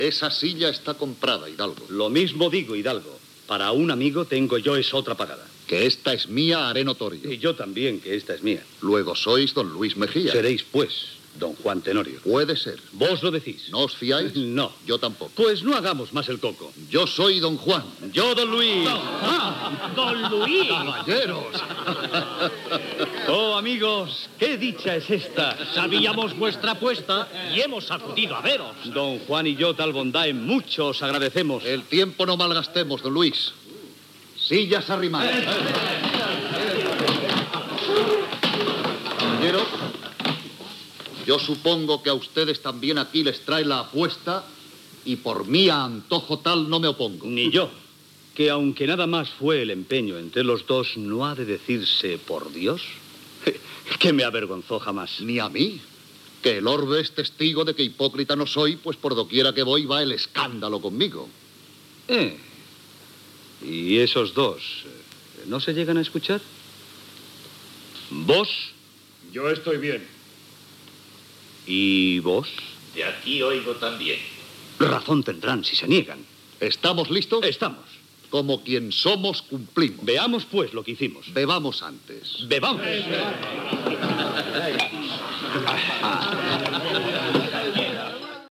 Adaptació radiofònica de l'obra "Don Juan Tenorio" de José Zorrilla.
Diàleg entre Don Juan i Don Luis Mejía
Ficció
Fragment extret del programa "La ràdio que vam sentir" emès l'1 d'agost de 1999.